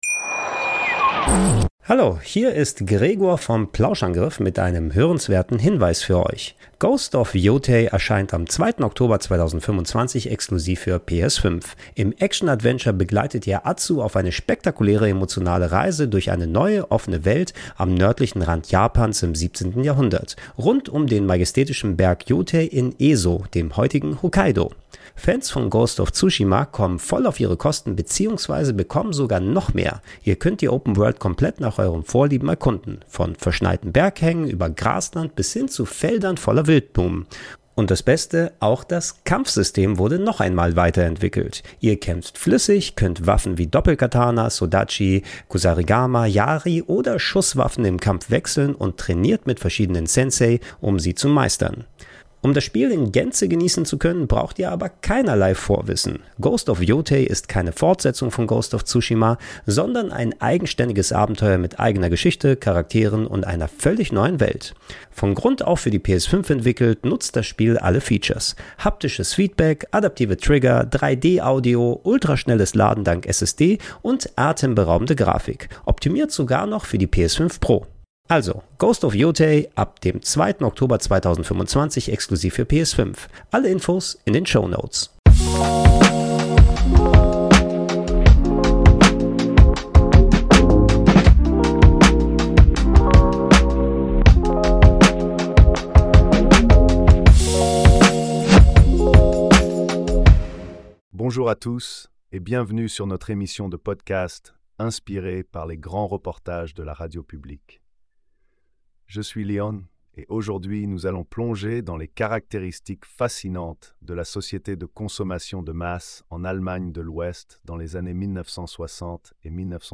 Ein Host mit amerikanischem Akzent.